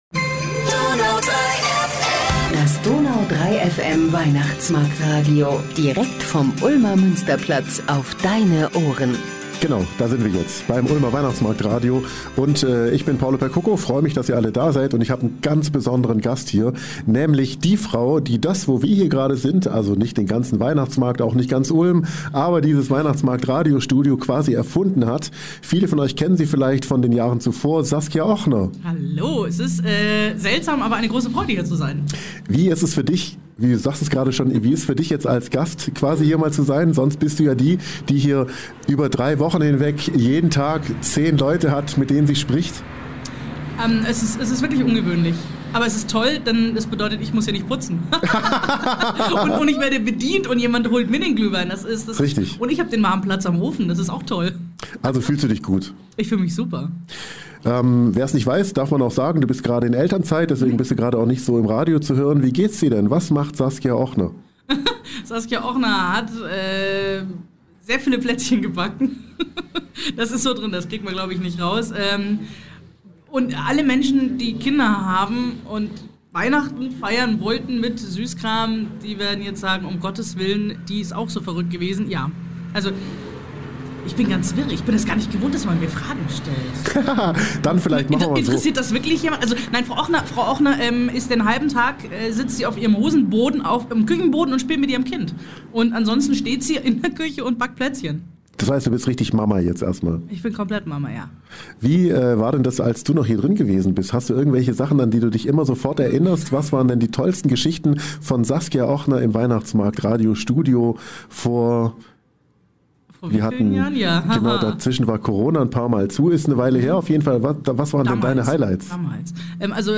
Direkt aus dem gläsernen Studio auf dem Ulmer Weihnachtsmarkt